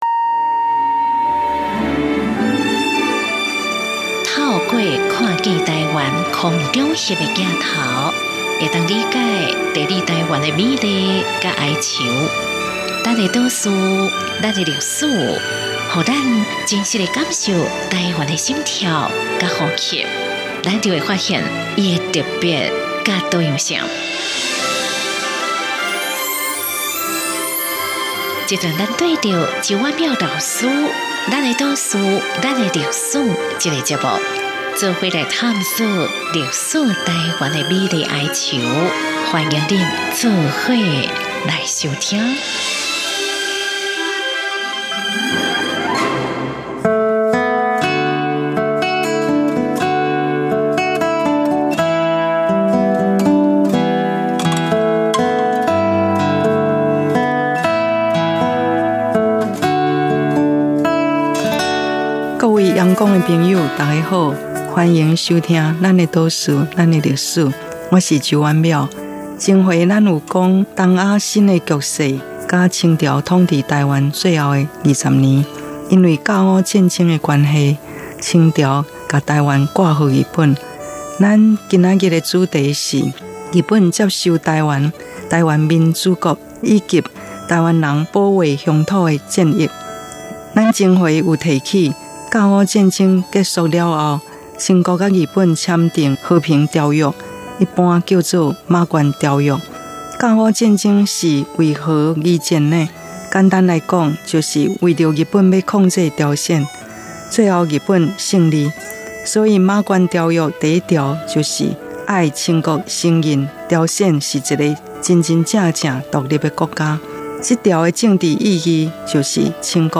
原文出處 節目名稱：Lán-ê 島嶼．lán-ê歷史 播出時間：2018年08月13日 主講者：周婉窈老師 Lán ê 島嶼．lán ê 歷史 透過「看見臺灣」空拍鏡頭，我們終於理解「地理臺灣」的美麗與哀愁。